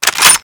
Index of /server/sound/weapons/m98